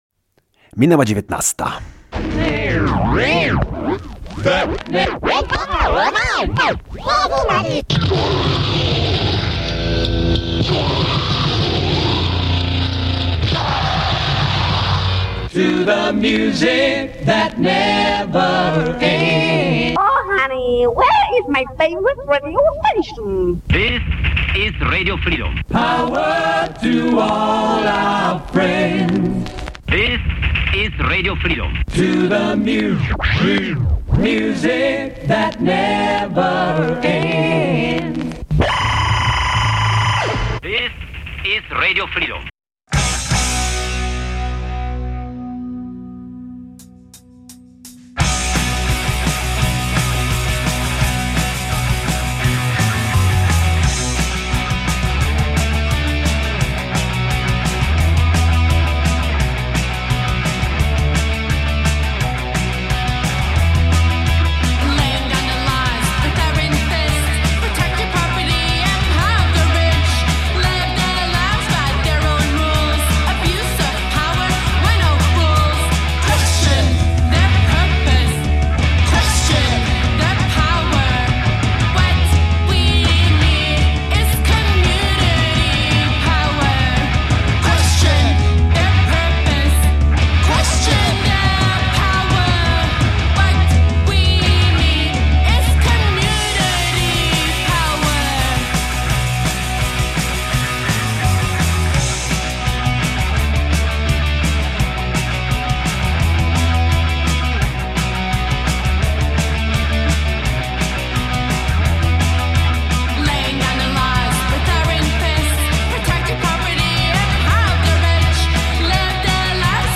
sporo anarcho peace punka z Kanady, USA i UK
Bristol jakiego się nie spodziewacie i … jazz.